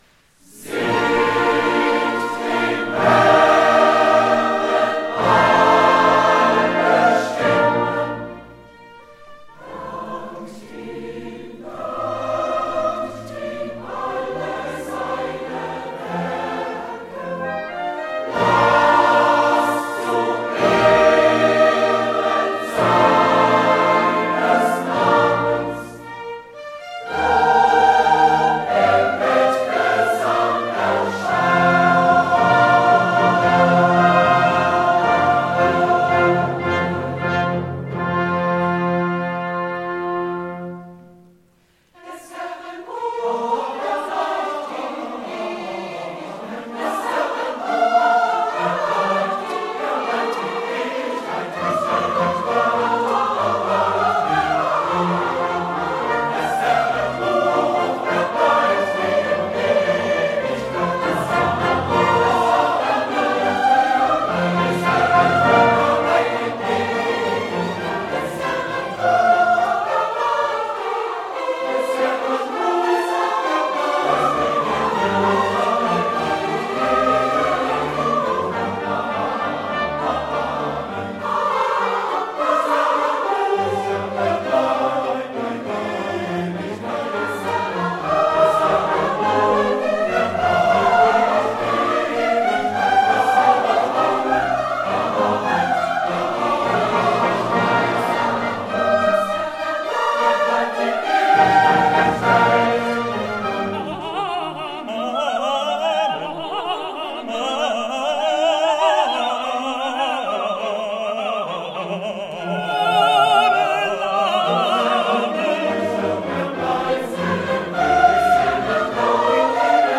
Der Dresdner Bachchor ist ein großer oratorischer Chor.